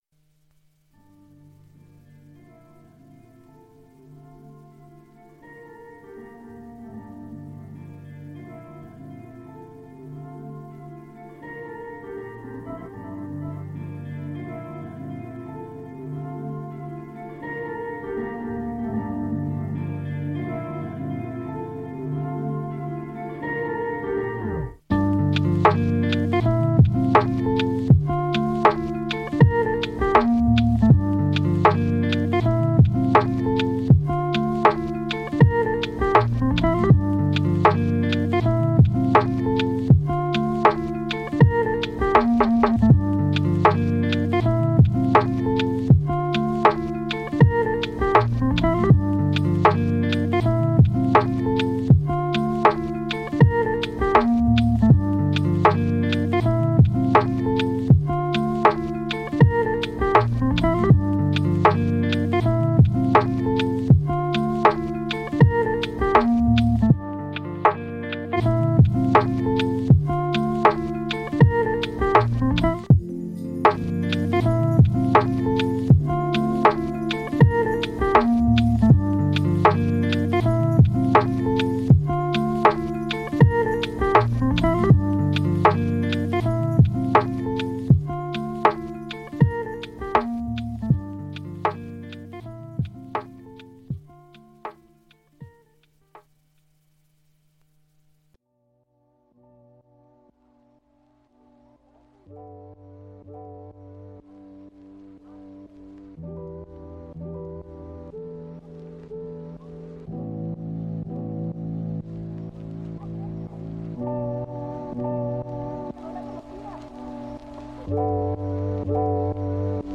Brahms : Ambiance Étude